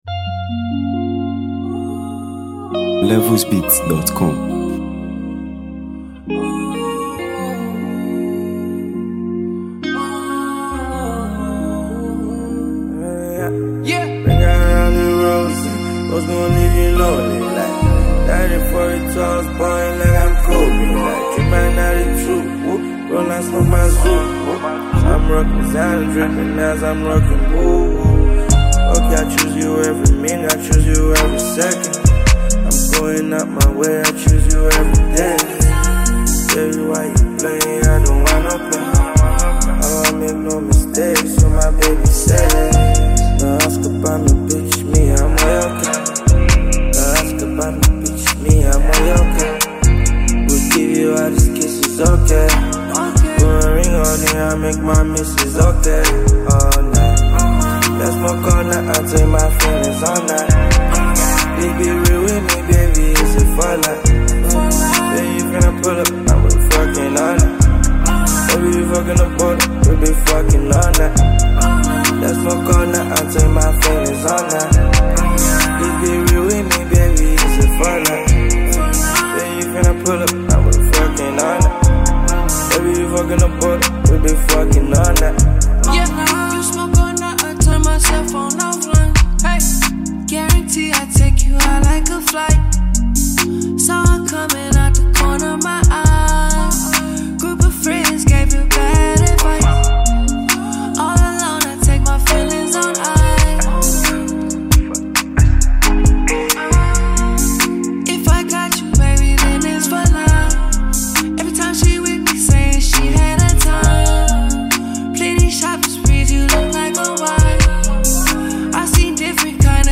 smooth vocals